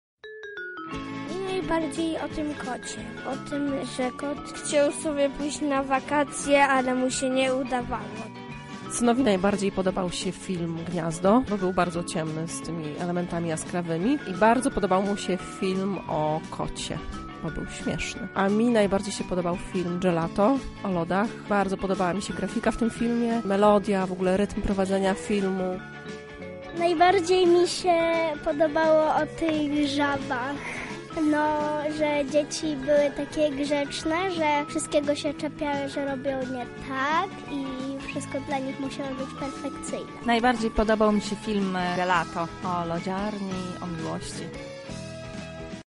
Dzieci oraz ich rodzice zdradzili, które filmy spodobały się im najbardziej.